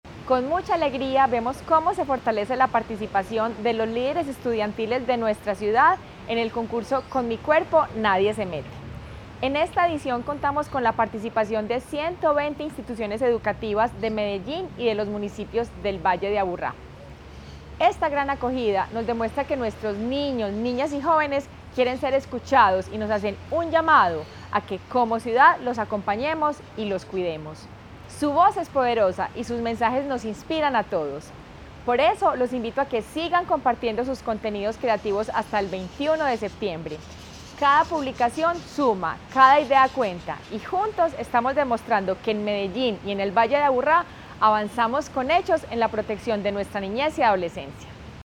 Declaraciones de la primera dama, Margarita María Gómez Marín
Declaraciones-de-la-primera-dama-Margarita-Maria-Gomez-Marin.mp3